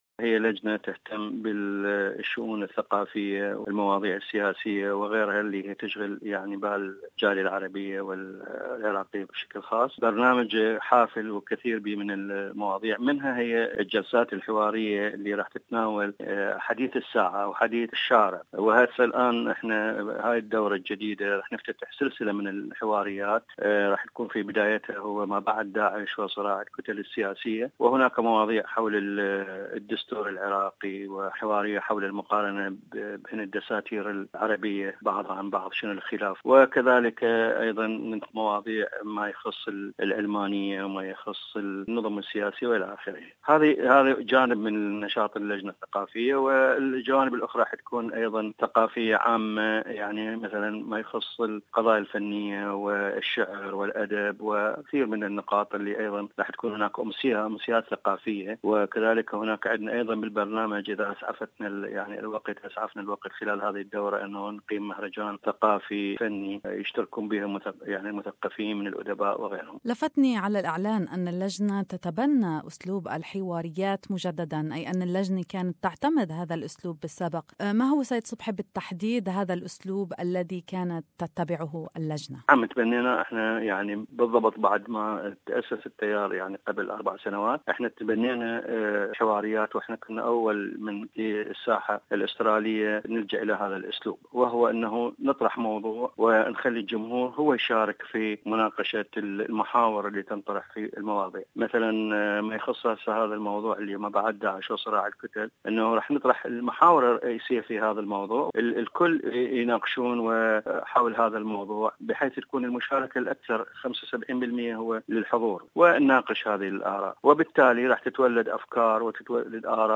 "After ISIS and the battle of Political Parties" conference in Sydney. More in the interview